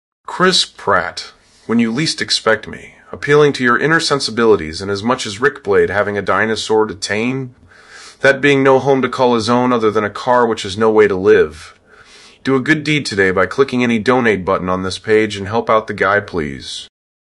Godlike voice for Godlike voice over and voice of God voice over, and requesting tax deductible donations for hurricane relief and homelessness.
Promo Demos